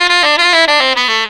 HARSH 2.wav